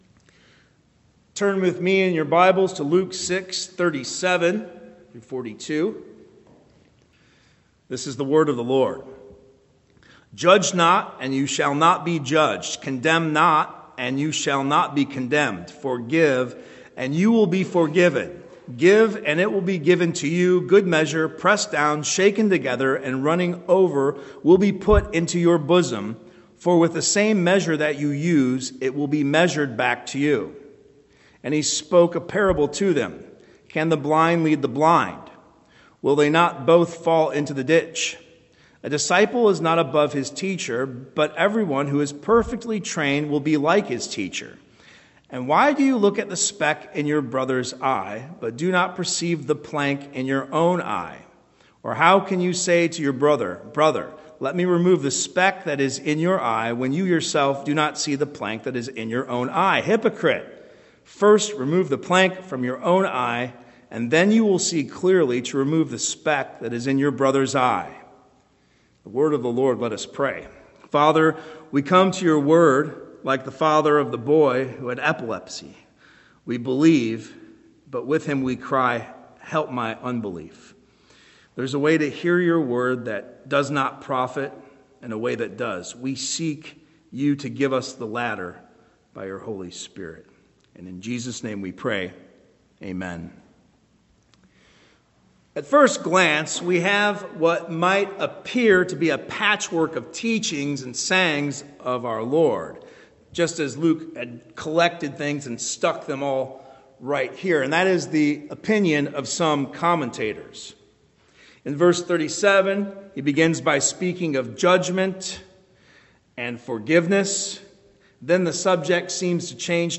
Sermons - Liberty Church